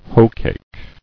[hoe·cake]